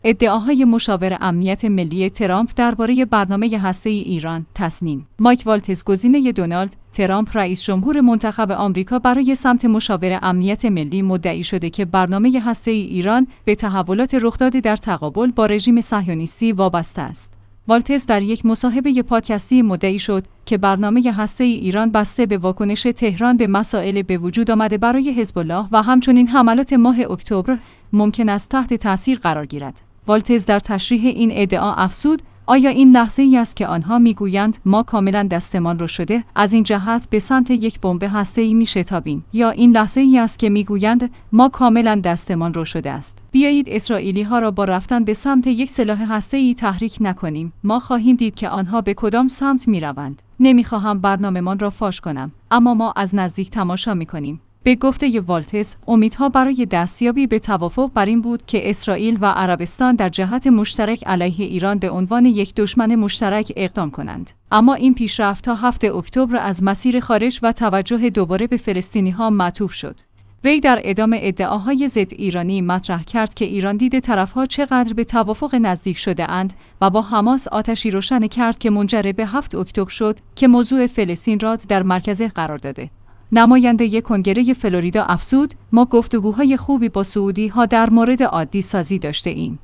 والتز در یک مصاحبه پادکستی مدعی شد که برنامه هسته‌ای ایران بسته به واکنش تهران به مسائل به وجود آمده برای حزب‌الله و همچنین